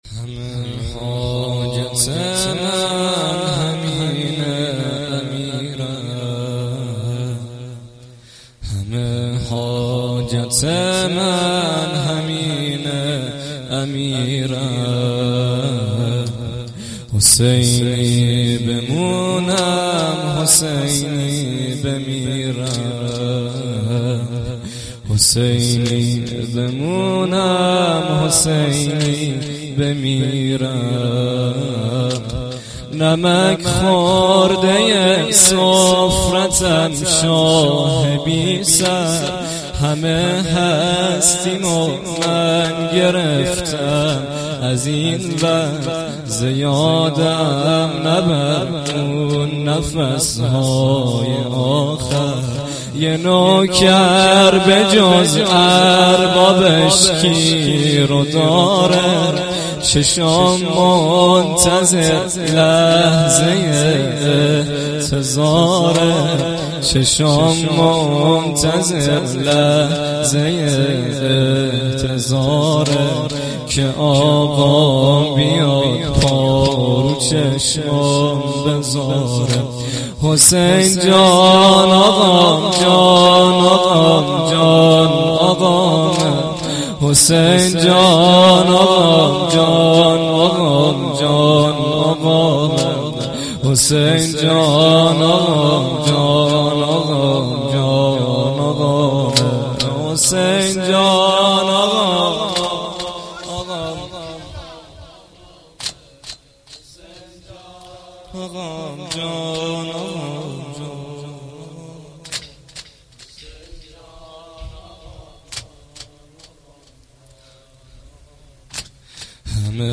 ذاکرالحسین
روضه العباس